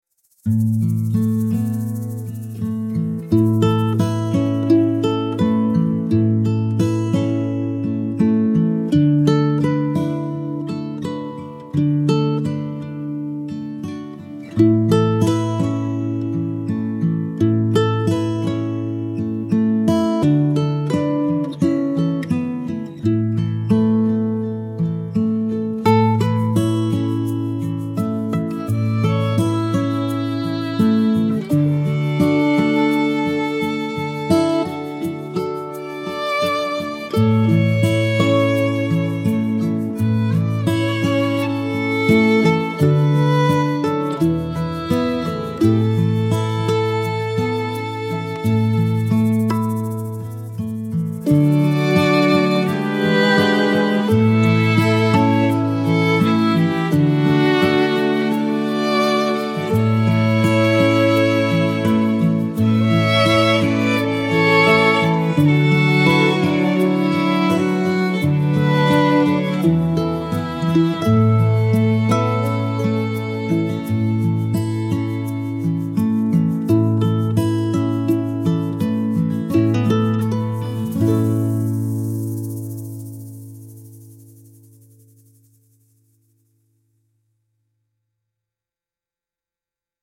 cozy fireside acoustic music with soft mandolin and humming cello